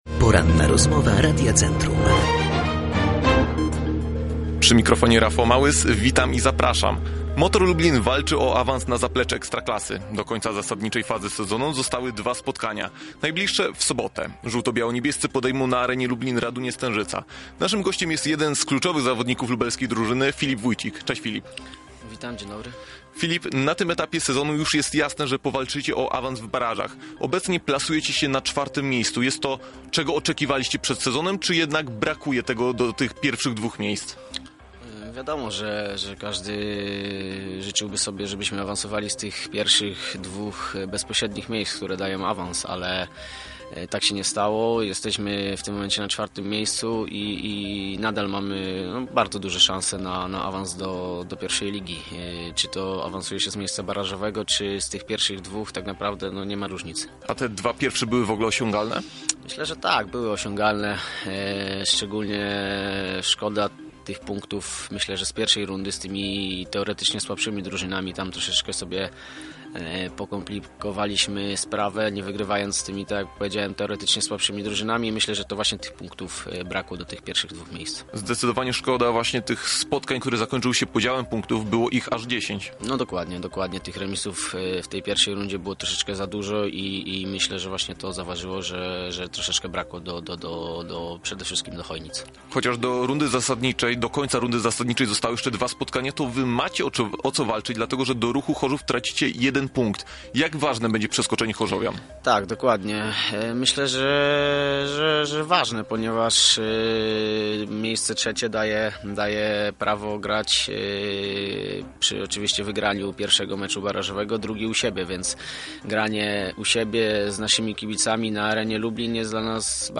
Rozmowa-po-edycji-1.mp3